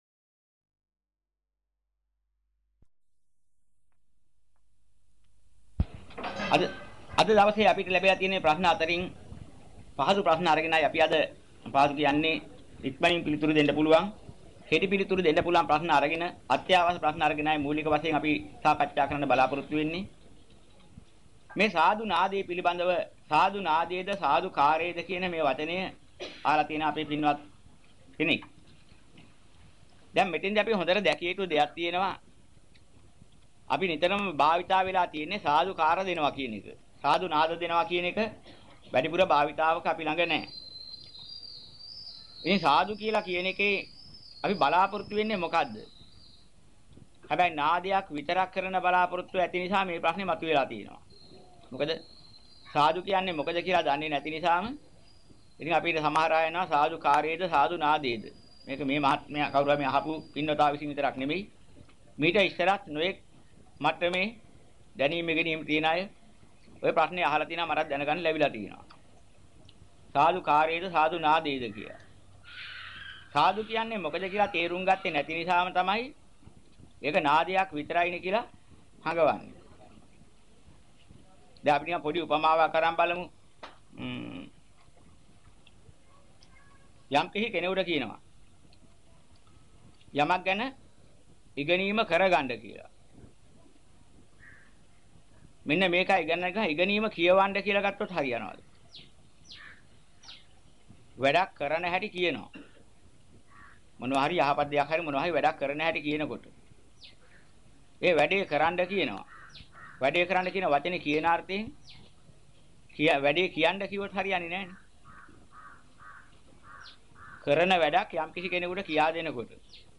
ධර්ම සාකච්ජා.